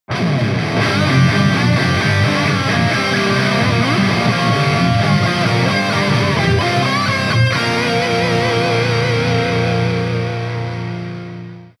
Toto su ukazky len gitar: